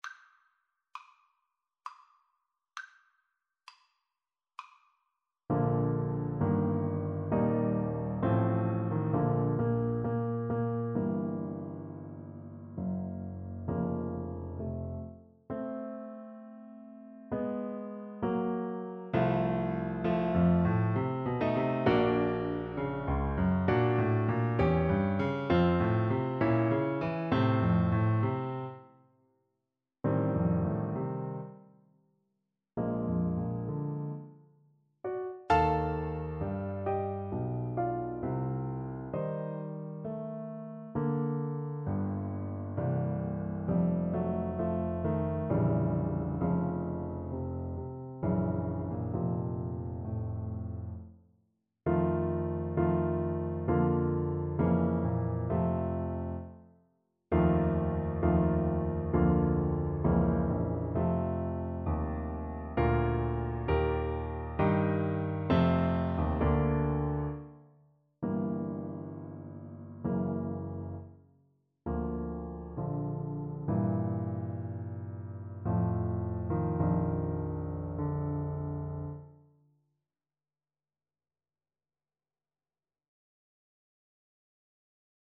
Play (or use space bar on your keyboard) Pause Music Playalong - Piano Accompaniment Playalong Band Accompaniment not yet available reset tempo print settings full screen
Bb major (Sounding Pitch) G major (Alto Saxophone in Eb) (View more Bb major Music for Saxophone )
3/4 (View more 3/4 Music)
=66 Andante sostenuto
Classical (View more Classical Saxophone Music)